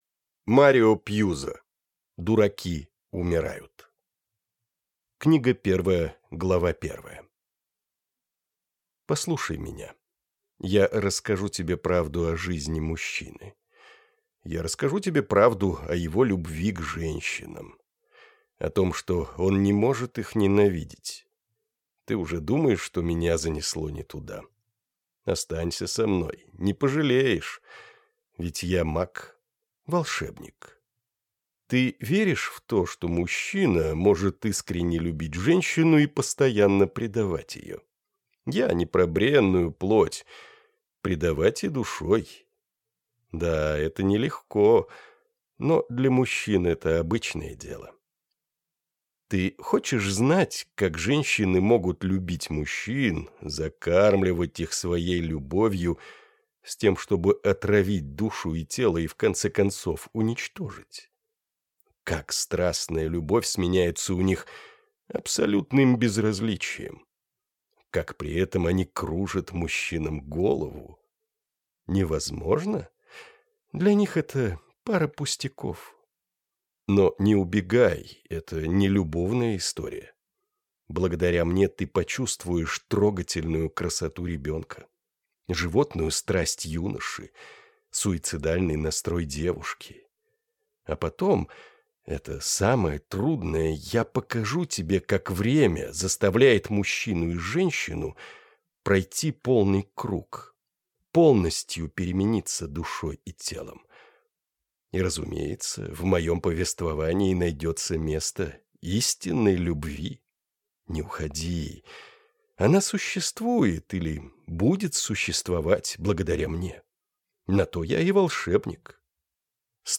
Аудиокнига Дураки умирают | Библиотека аудиокниг